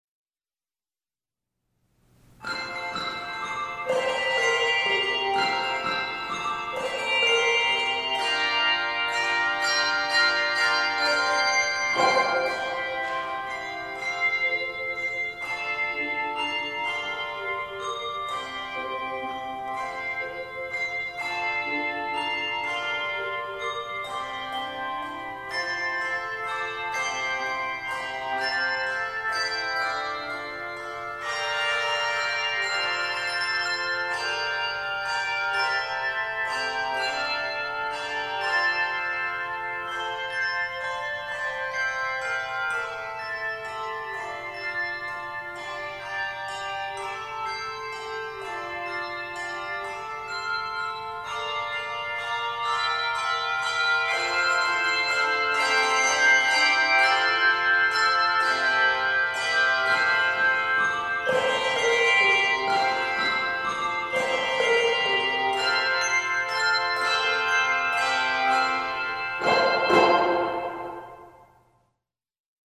joyful arrangement